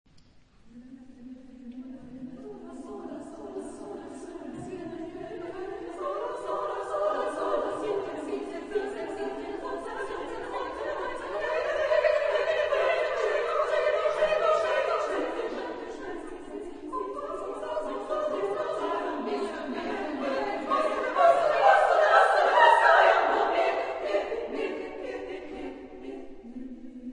Género/Estilo/Forma: Canto coral ; Profano
Tipo de formación coral: SSAA  (4 voces Coro femenino )
Tonalidad : libre